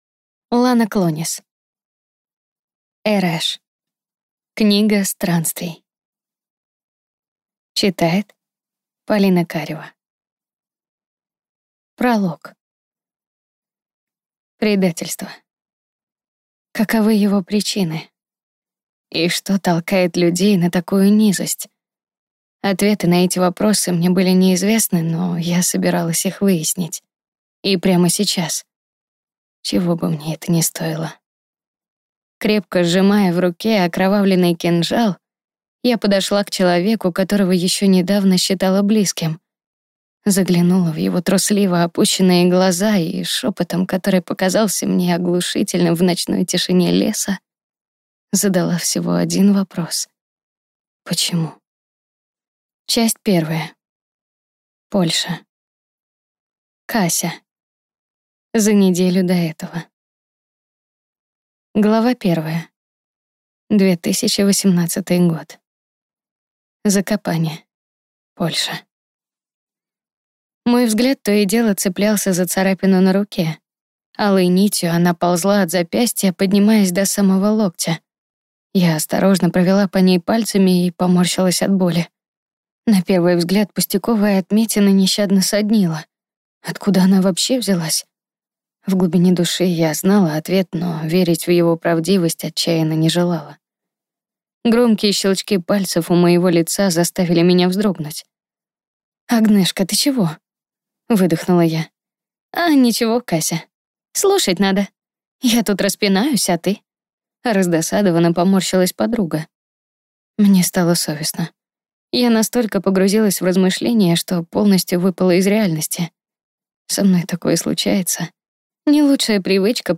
Аудиокнига Эреш. Книга странствий | Библиотека аудиокниг